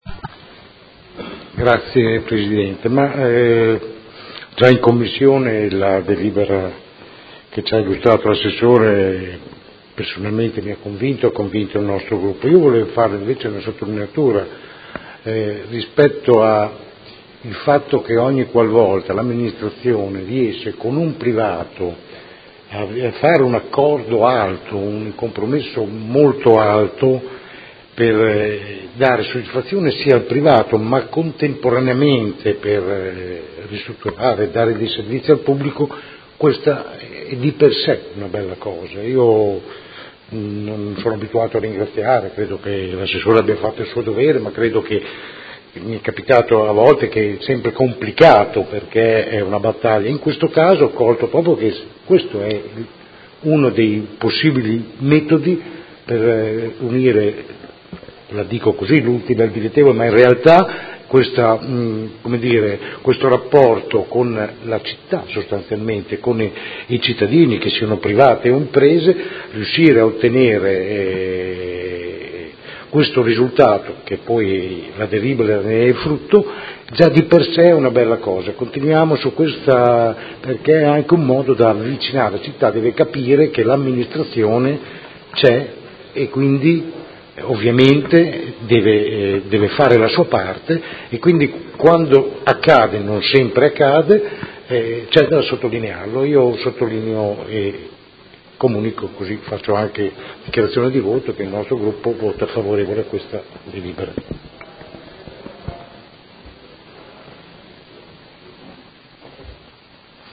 Francesco Rocco — Sito Audio Consiglio Comunale
Seduta del 13/07/2017 Dibattito.